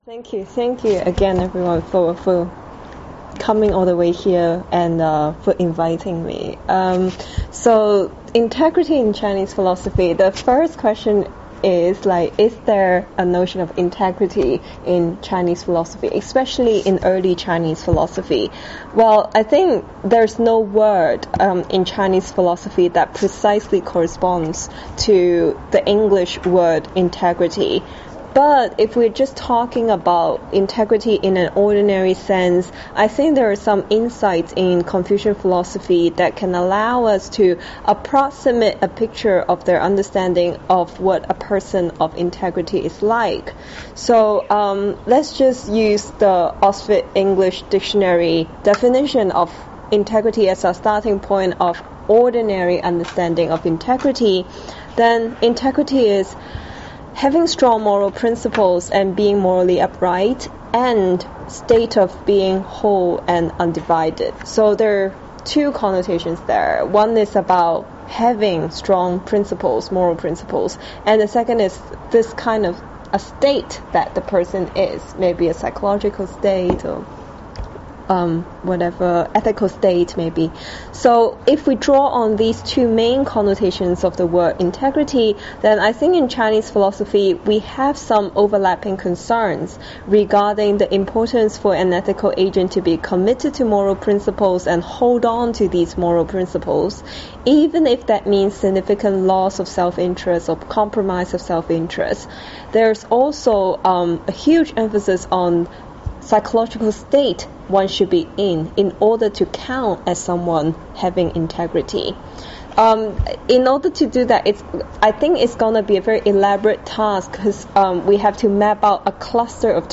Introductory readings (mp3 file)